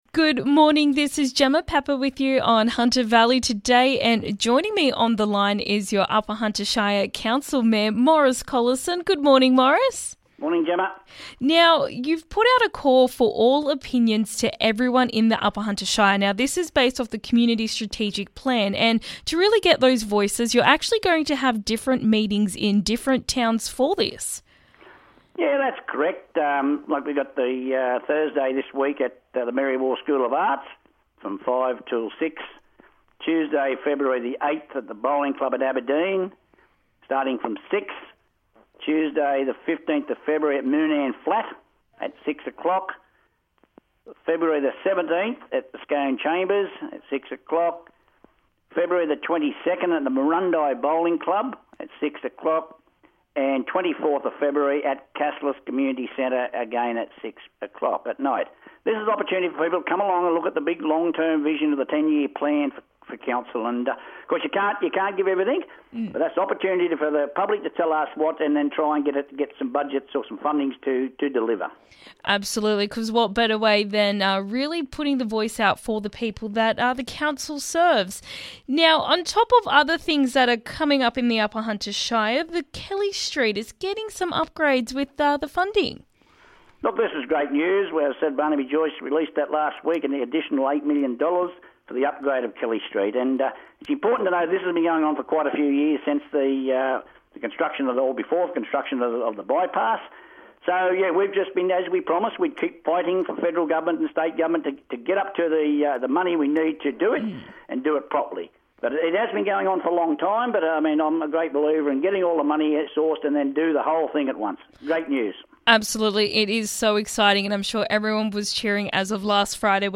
New funding announcements, strategic plans and Warbirds Over Scone. Upper Hunter Shire Mayor Maurice Collison explains what is happening this week in the region!